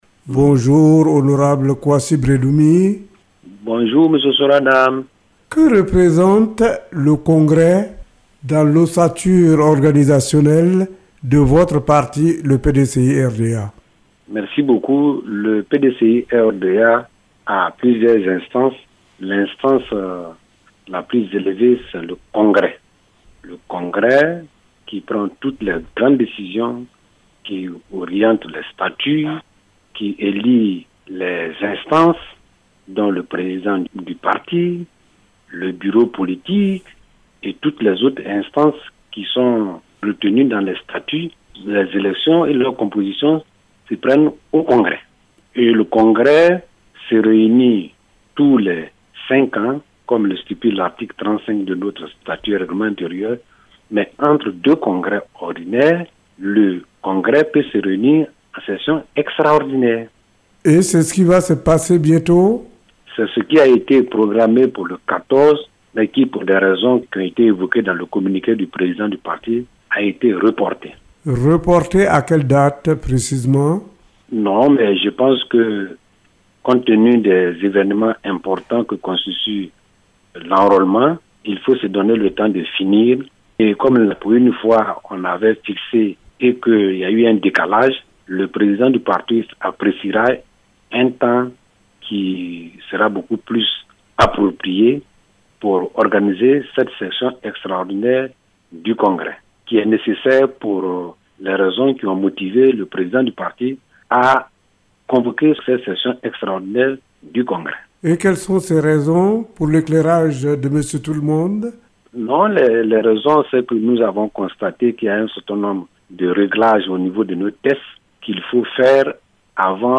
Et il répond aux questions